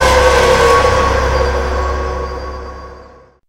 horror3.wav